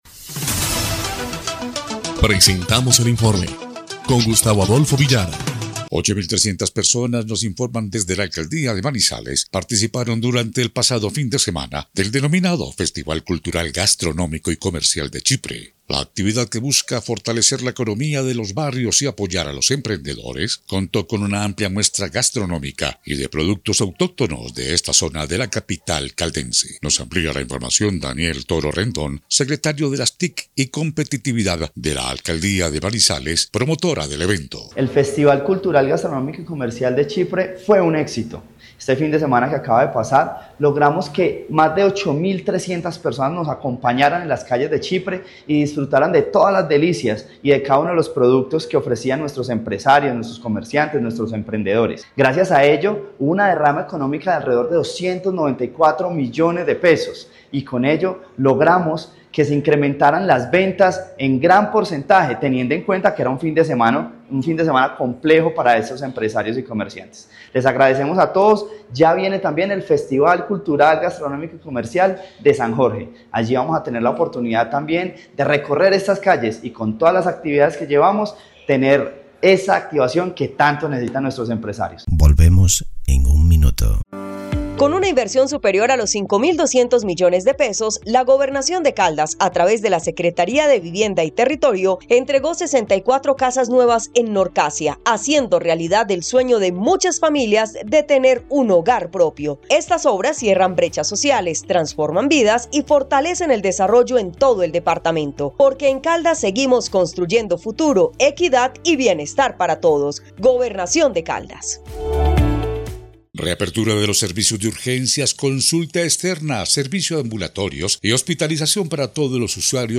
EL INFORME 3° Clip de Noticias del 28 de octubre de 2025